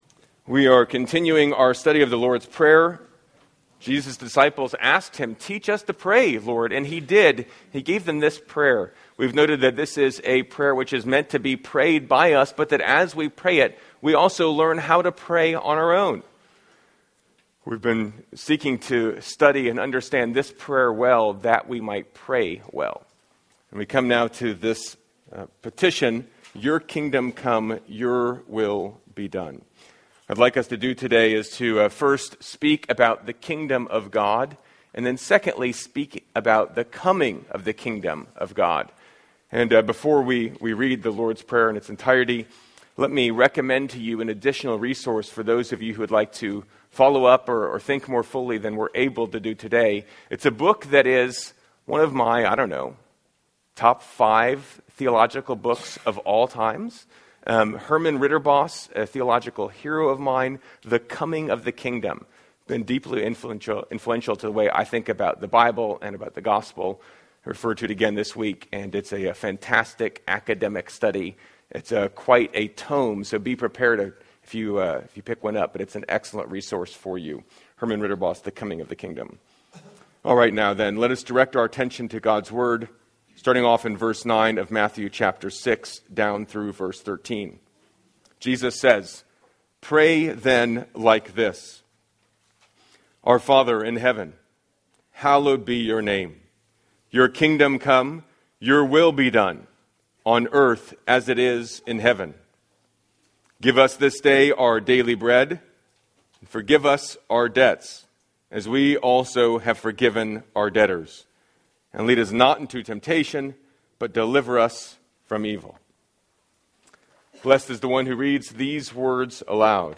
A six-week sermon series on The Lord’s Prayer.